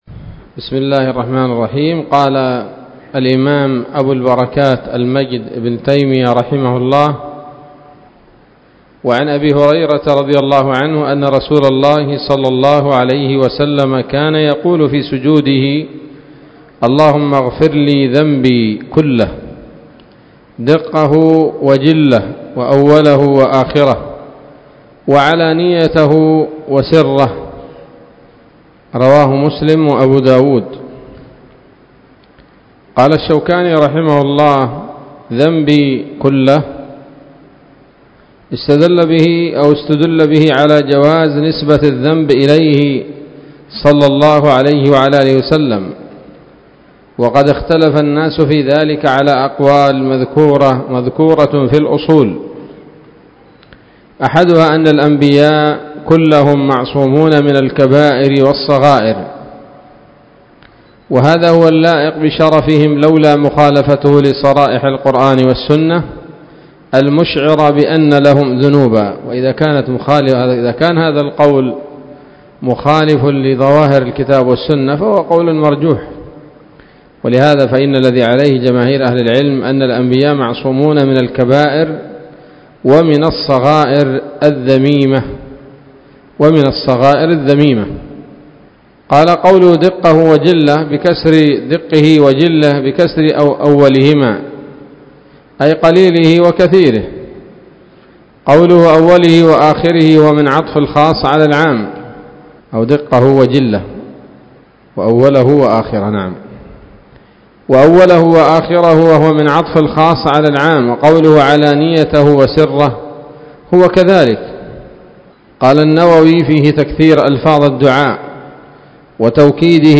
الدرس السابع والثمانون من أبواب صفة الصلاة من نيل الأوطار